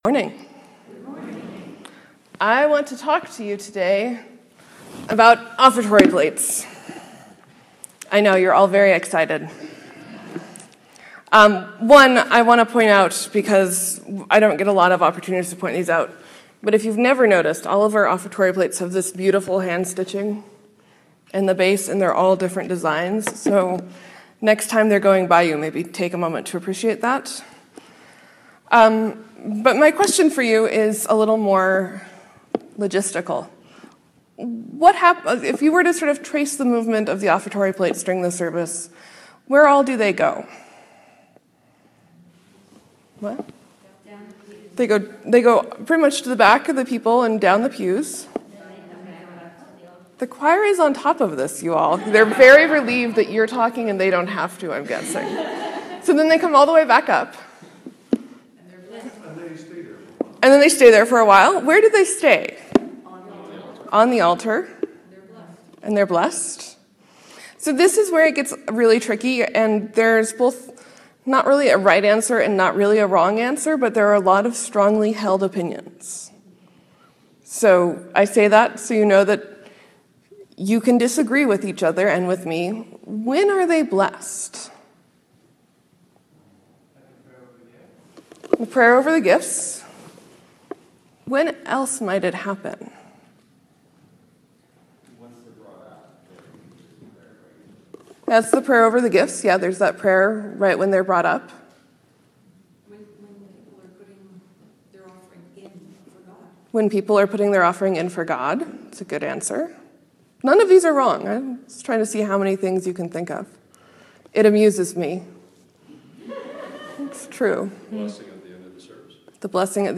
Sermon: This was a hard week full of horrible news.